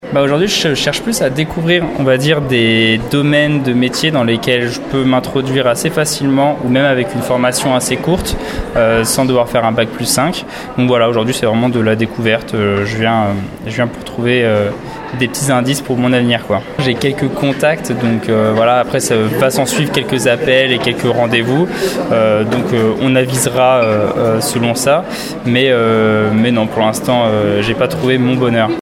Forte affluence hier après-midi au forum Destination emploi.